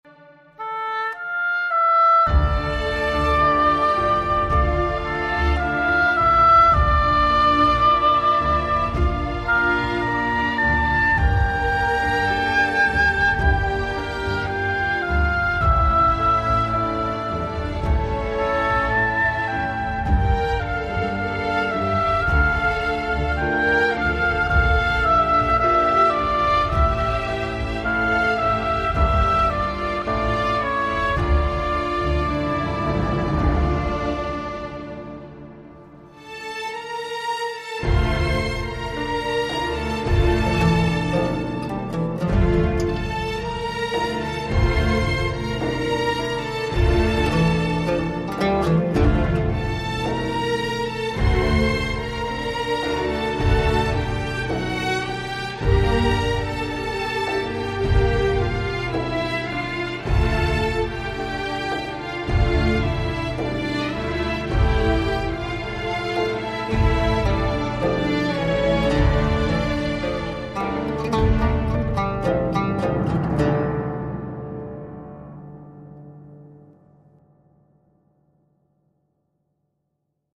زیبا و حماسی خدا اجرتان دهد